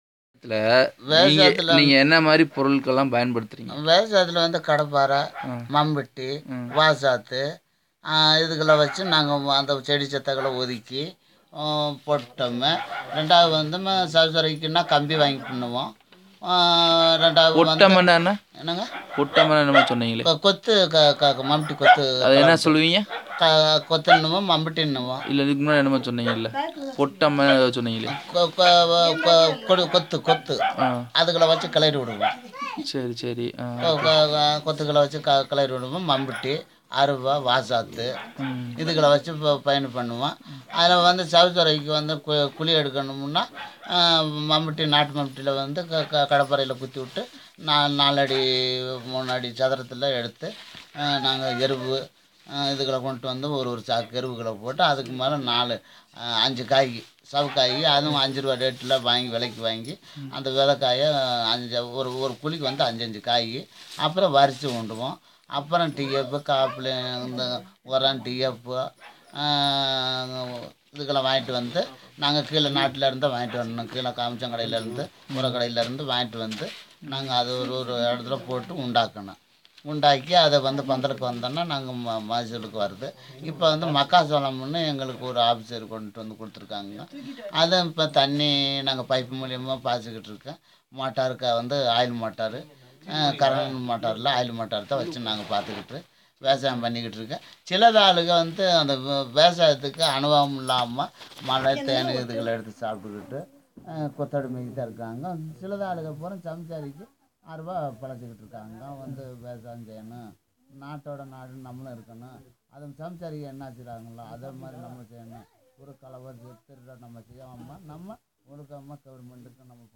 Conversation about tools used in the field for farming
NotesThis is a conversation between the consultant and the principal investigator about the names of tools used in the field for farming.